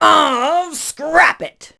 pam_die_vo_02.wav